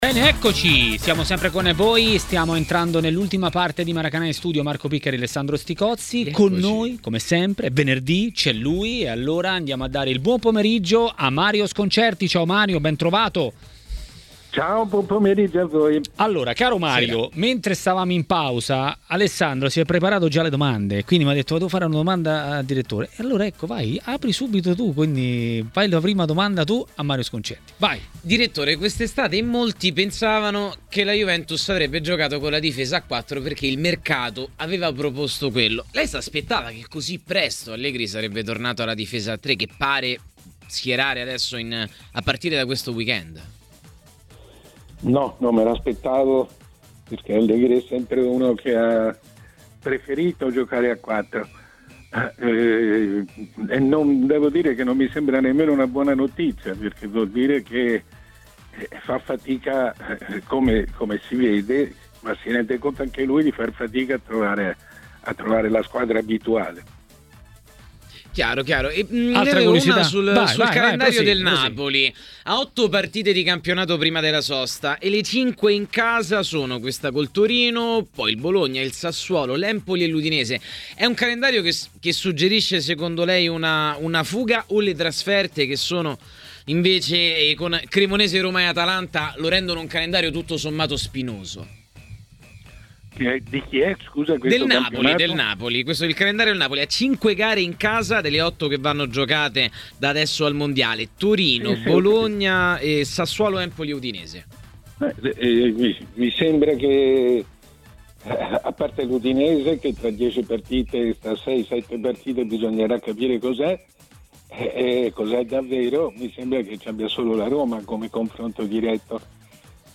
A commentare la Serie A a Maracanà, trasmissione di TMW Radio, è stato il giornalista Mario Sconcerti.